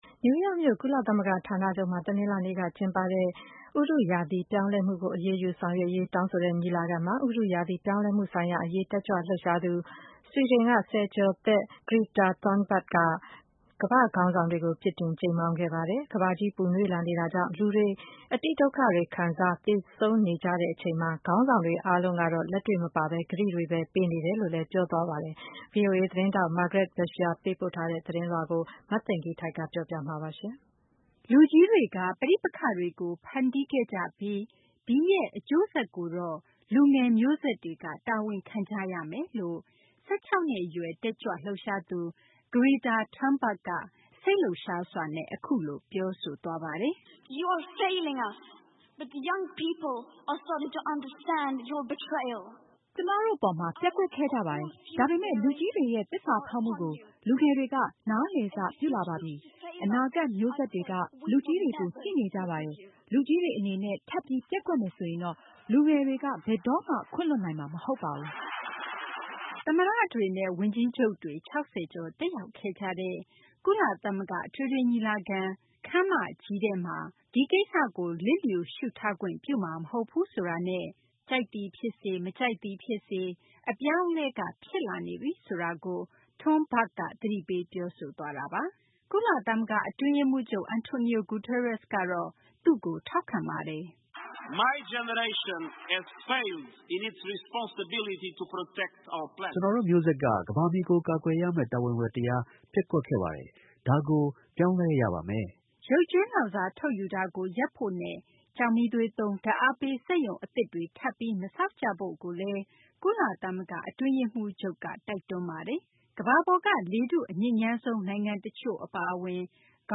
လူကွီးတှကေ ပဋိပက်ခတှကေို ဖနျတီးခဲ့ကွပွီး ဒီရဲ့ အကြိုးဆကျကိုတော့ လူငယျ မြိုးဆကျတှကေ တာဝနျခံကွရမယျလို့ ၁၆ နှဈ အရှယျ တကျကွှလှုပျရှားသူ Greta Thunberg က စိတျလှုပျရှားစှာ အခုလို ပွောဆိုသှားပါတယျ။